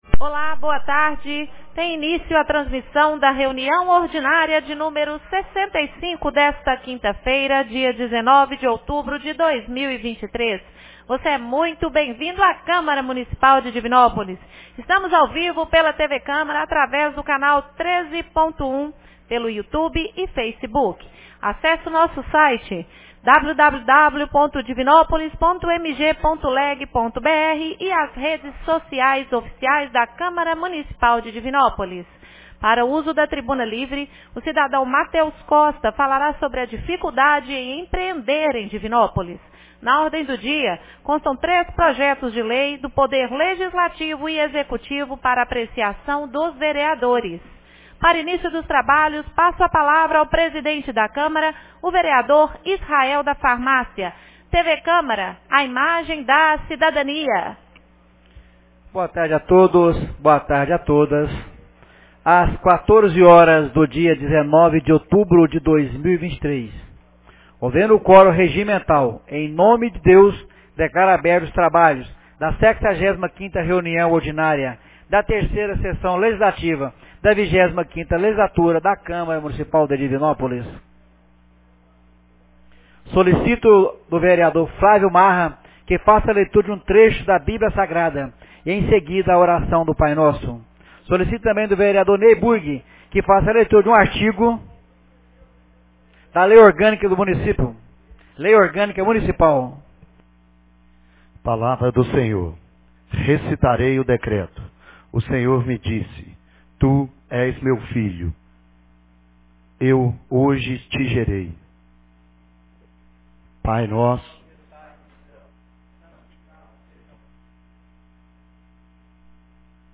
65ª Reunião Ordinária 19 de outubro de 2023